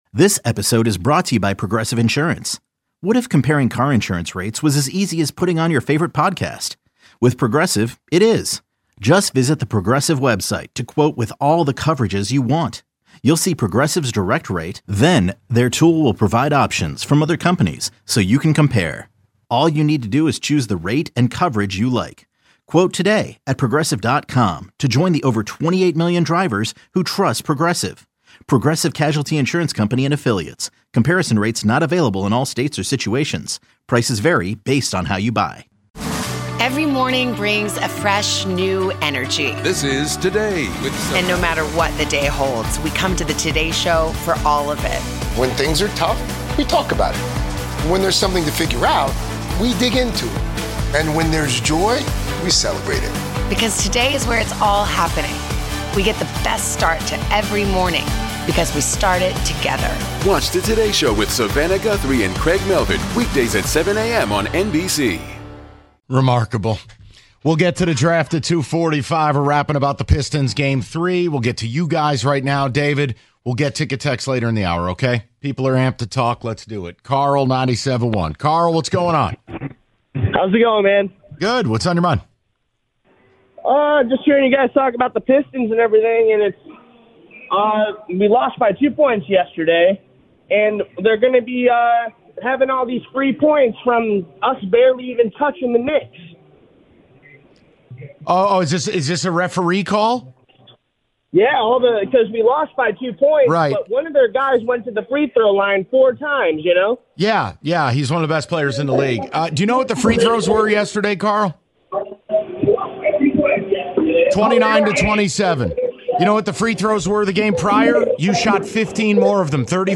Taking Your Calls On Pistons' Loss, Jalen Brunson/Knicks' Performance